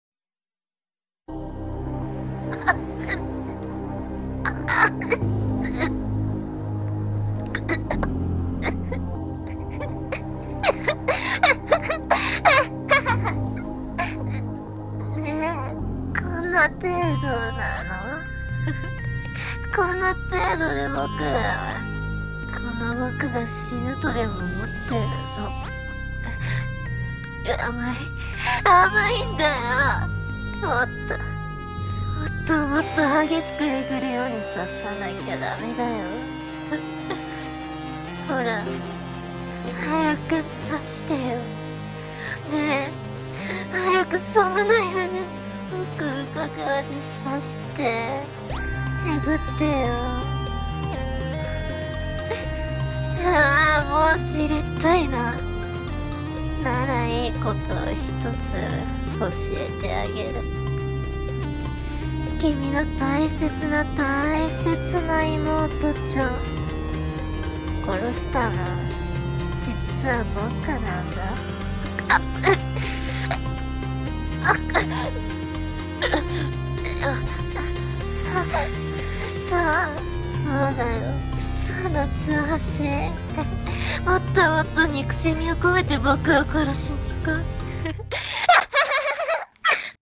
【一人声劇】狂人受け［グロ注意］